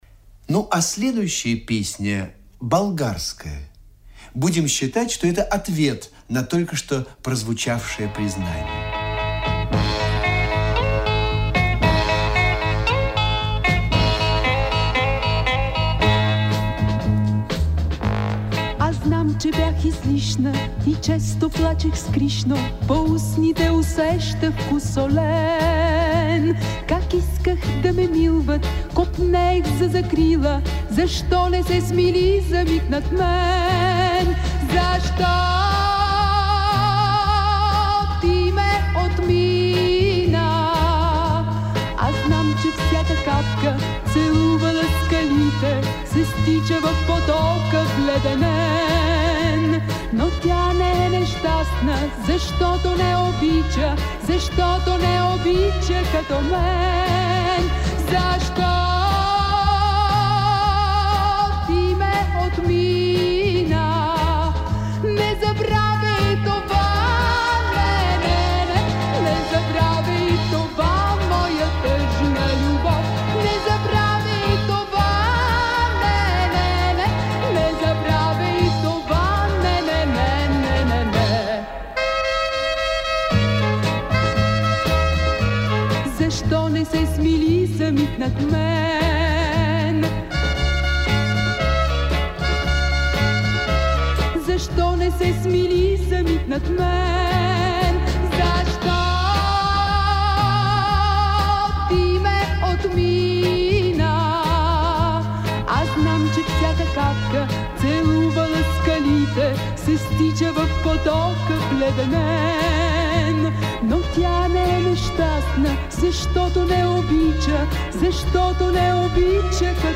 Прозвучала на радио в 1970 году